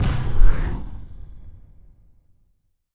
Cannon [Fire].wav